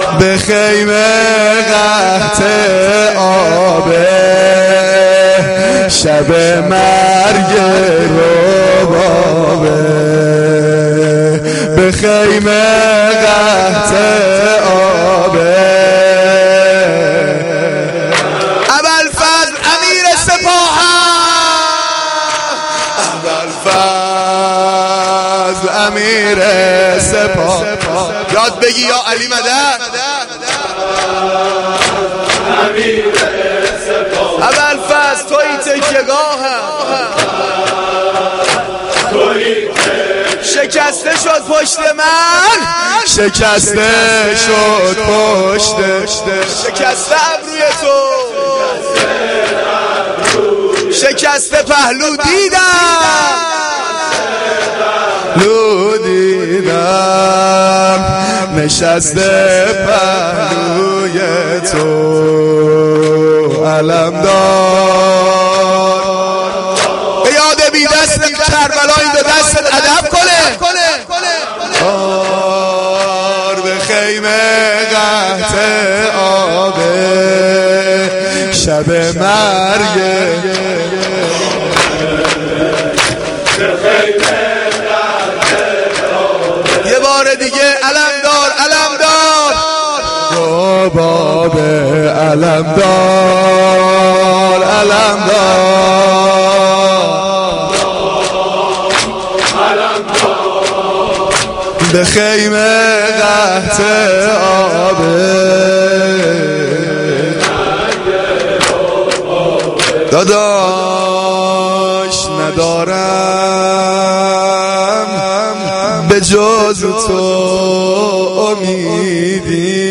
مداحی
Shab-9-Moharam-4.mp3